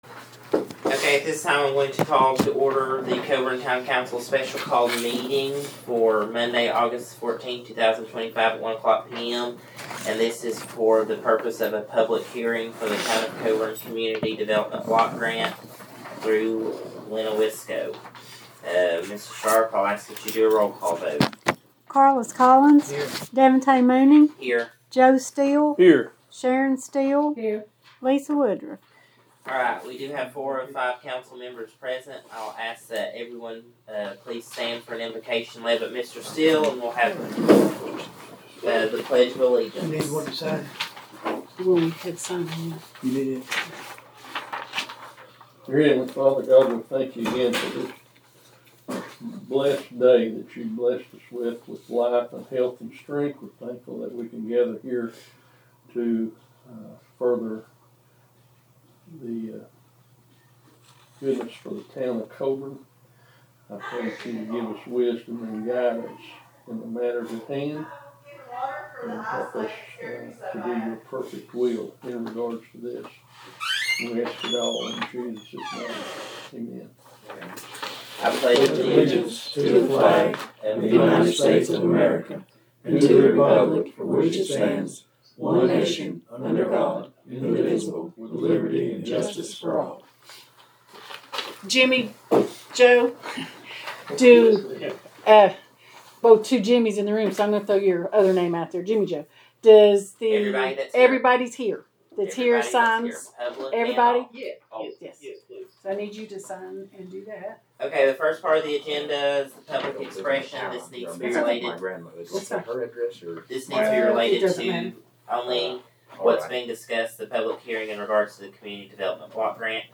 Town of Coeburn, Virginia - Town Council Public Hearing Meeting